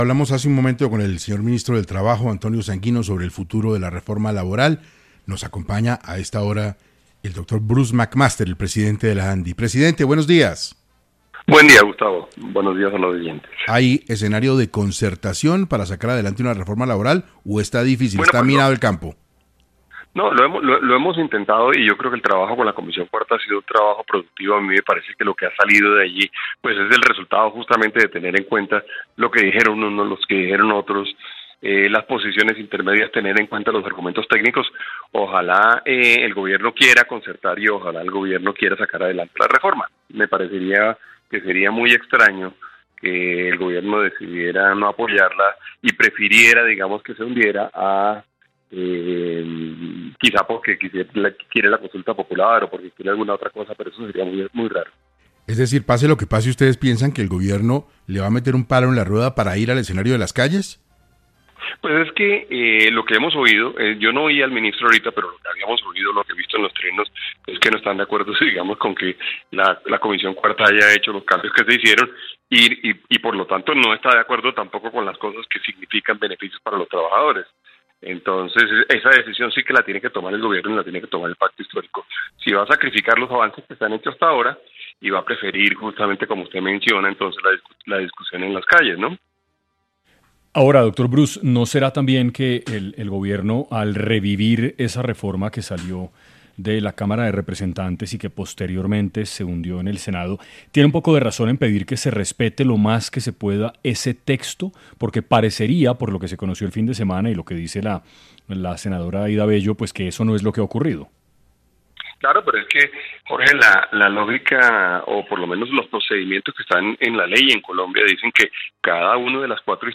El presidente de la ANDI, Bruce Mac Master, habló para los micrófonos de 6AM, sobre las peticiones que el gremio pide al Gobierno tener en cuenta para implementar una reforma laboral armoniosa y beneficiosa para todo el país.